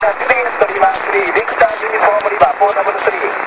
ちなみに移動地は清水市駒越海岸。
SP　SAMPLE-2　５エレでの受信　(REAL AUDIO)
SAMPLE-2では、５エレの方が安定かつ強力に聞こえます。